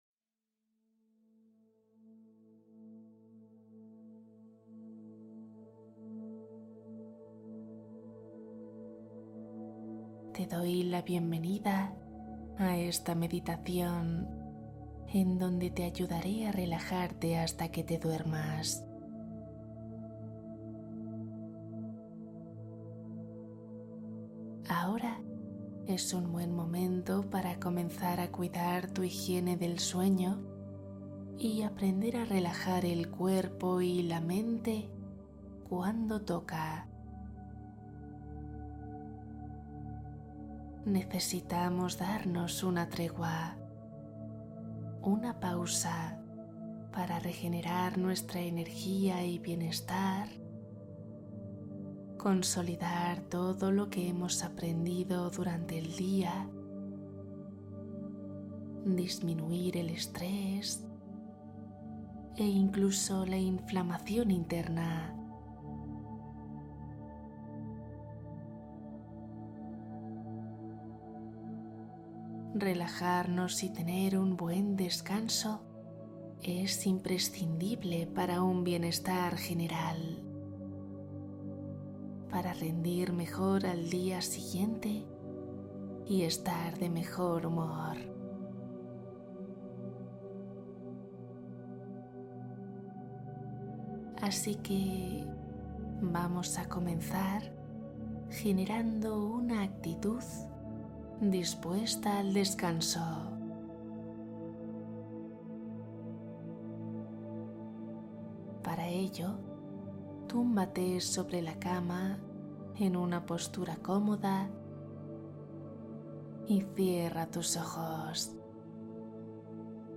Meditación para Dormir